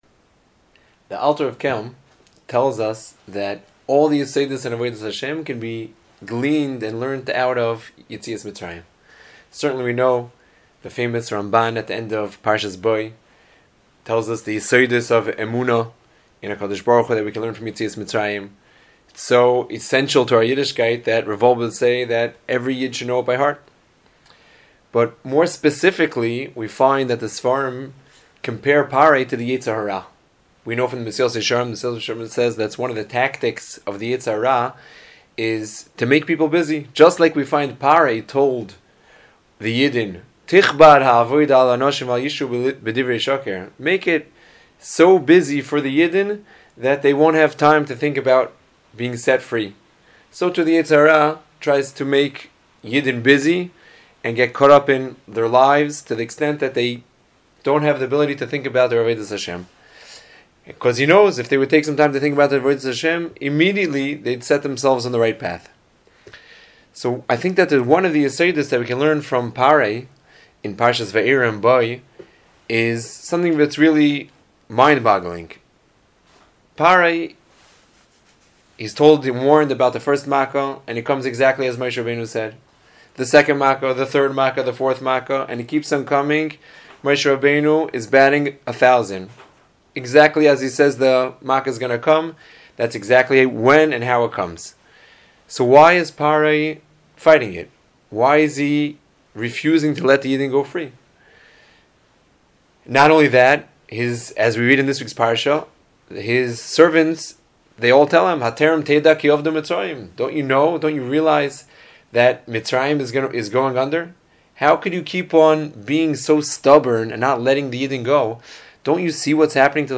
Parsha Preview Audio